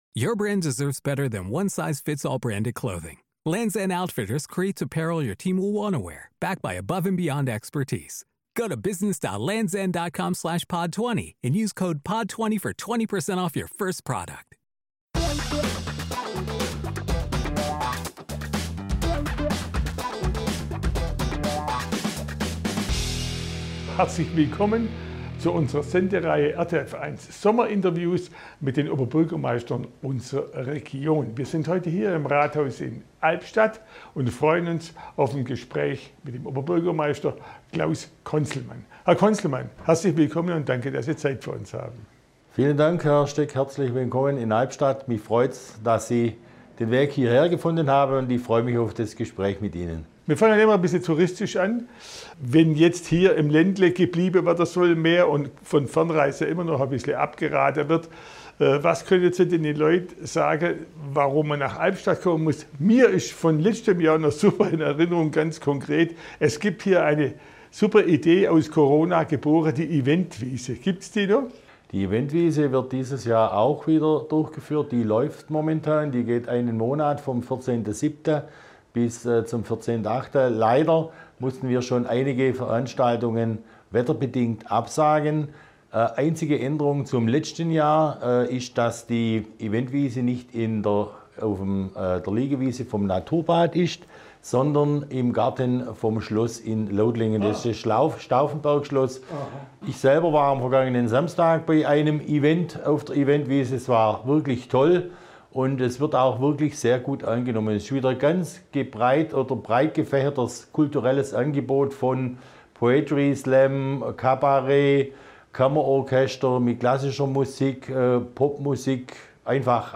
Unser Sommerinterview mit dem OB von Albstadt im Zollernalbkreis, Klaus Konzelmann (Freie Wähler).
Klaus-Konzelmann-OB-Albstadt-Sommerinterview-2021.mp3